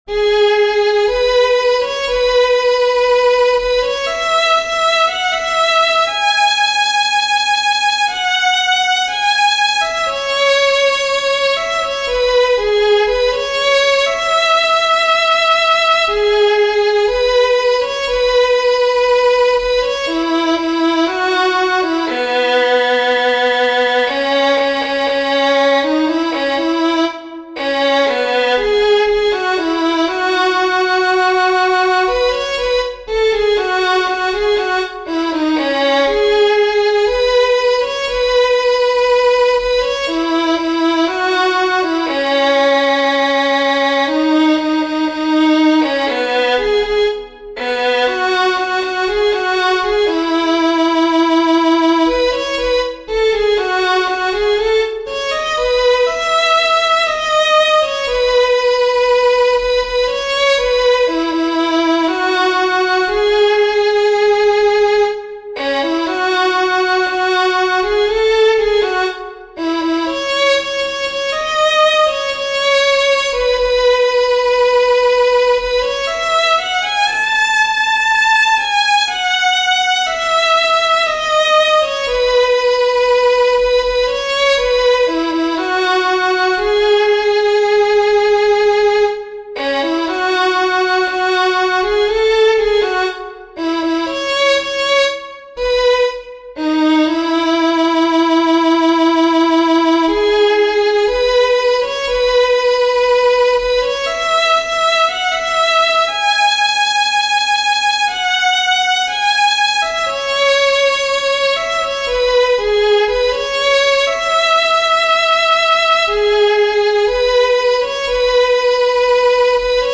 由于没有时间与资源制作伴奏，我附上的都只是用单一MIDI乐器制作的简易旋律示范。